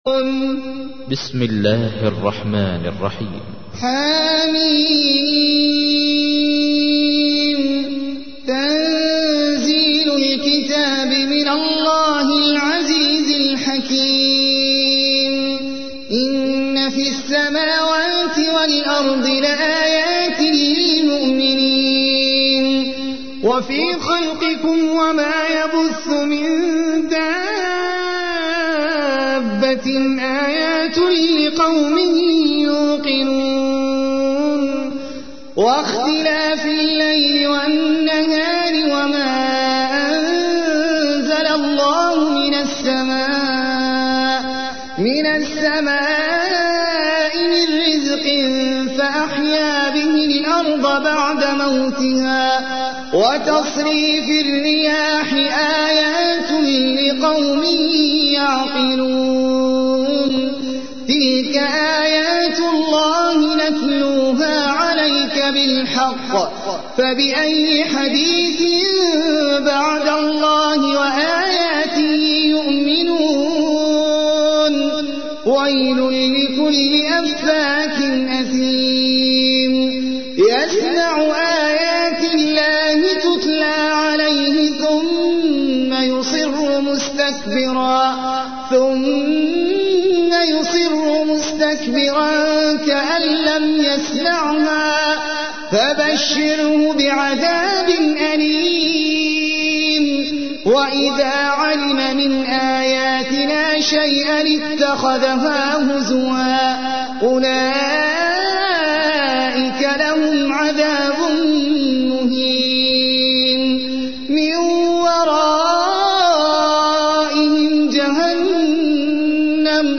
تحميل : 45. سورة الجاثية / القارئ احمد العجمي / القرآن الكريم / موقع يا حسين